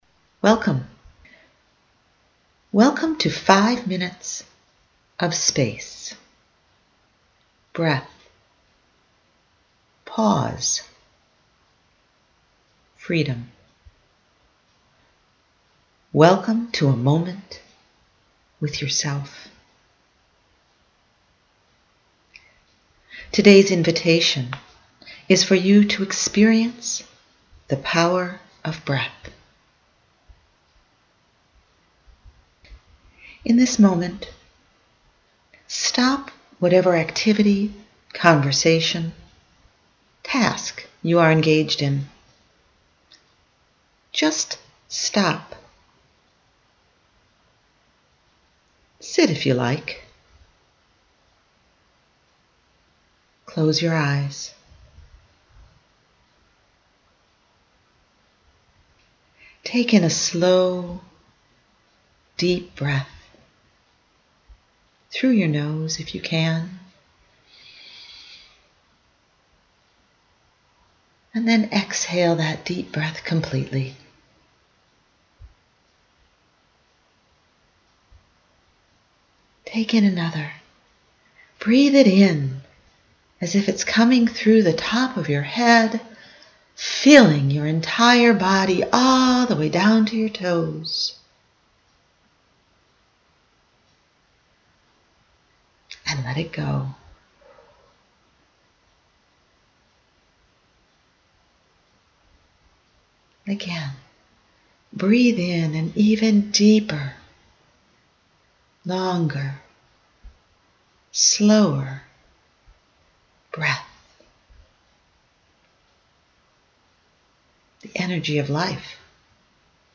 Space-1-BreatheAndReleaseBodyScan.mp3